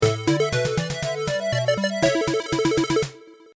アイキャッチやジングルに使えそうな短いフレーズ
ファンファーレ_1 宝箱とかをゲットしたときとか